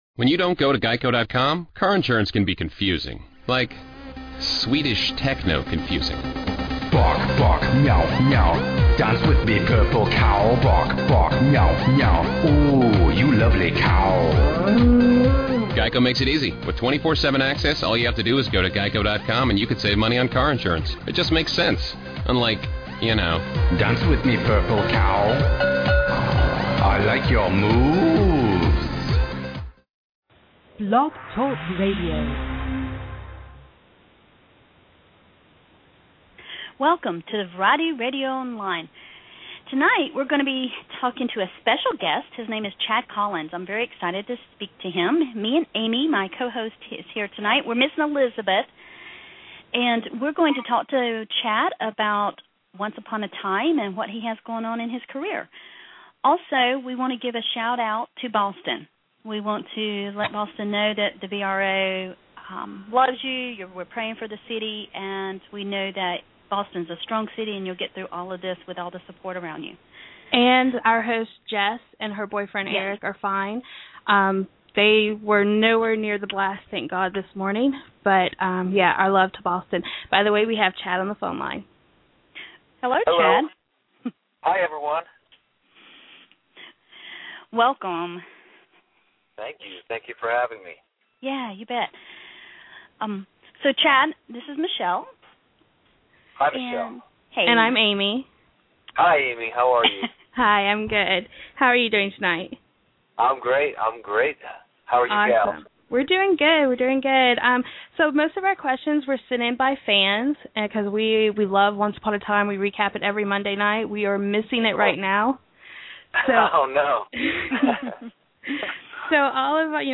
Chad Michael Collins 2013 Interview